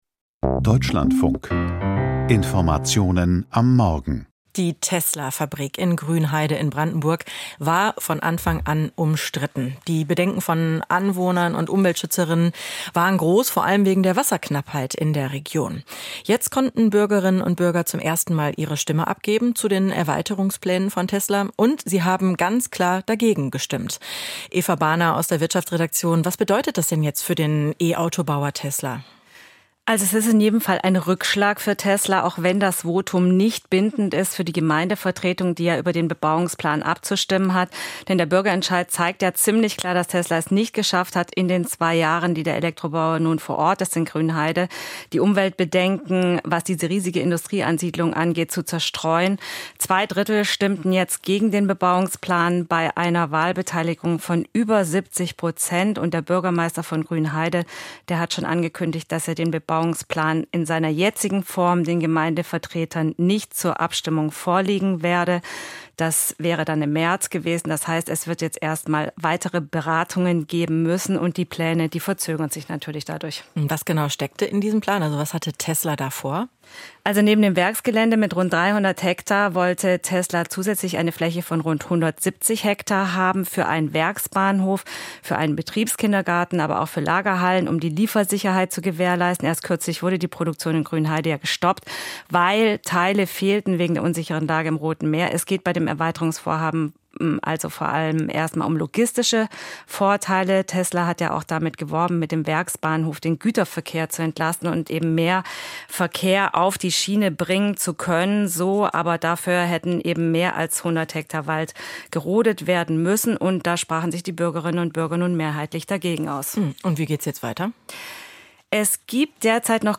Wirtschaftsgespräch: Der Schlingerkurs von Tesla